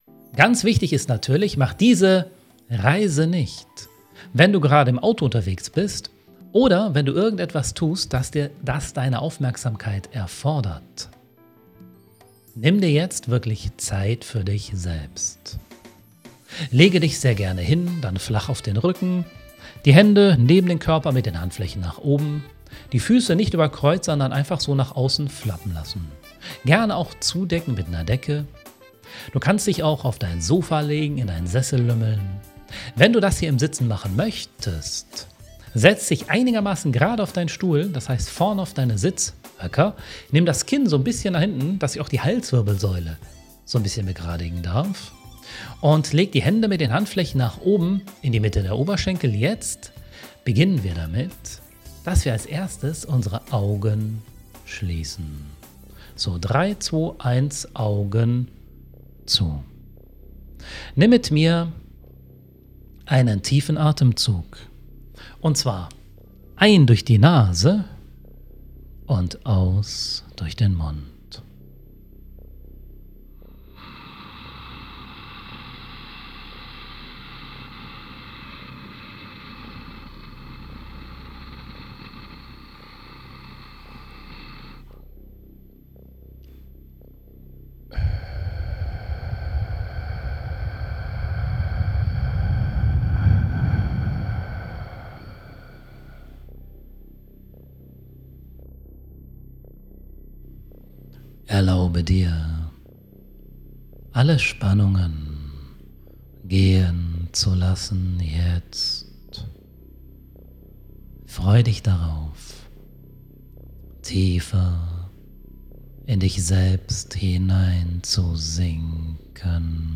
Premium Meditation zum Auflösen der aktuellen Überforderungund Aktivieren Deiner Lebensfreude
Denn diese Meditation ist eine Live Aufzeichnung von einem unserer "Sönnchen Live Club" Treffen.
changenow-Premium-Strand-Goldenes-Licht-Meditation.mp3